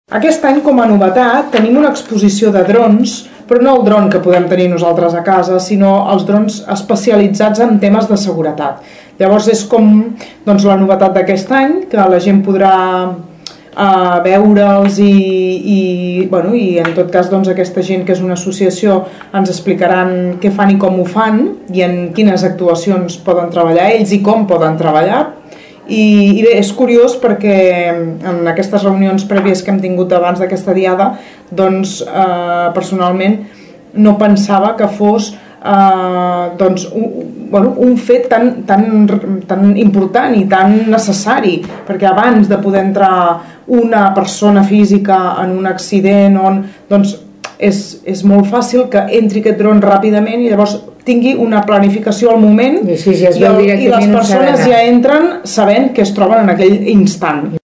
Una de les novetats principals d’aquesta diada serà la presentació especial de la utilització de drons. La regidora de Via Pública Sílvia Català ens informava de la tasca que realitzarà aquest nou element amb els possibles sinistres o actuacions.